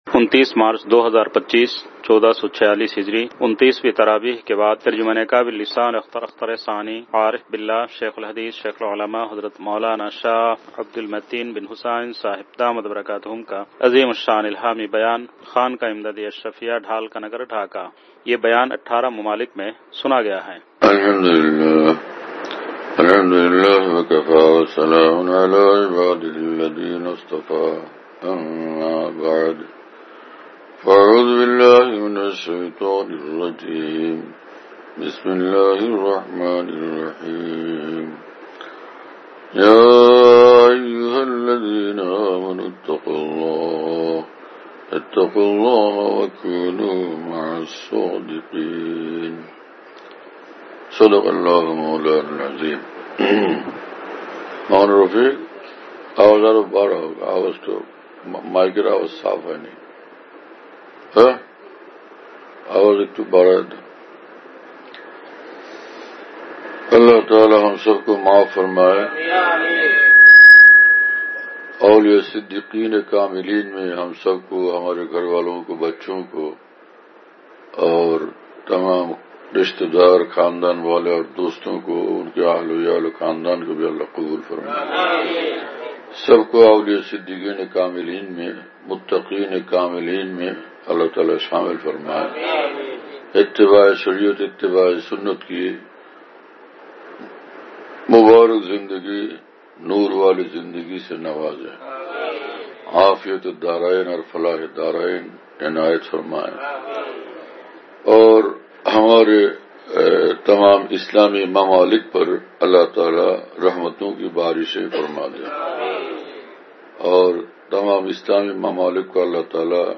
۲۹ مارچ ۲۰۲۵ءبعد تراویح : ۲۹ شب رمضان المبارک !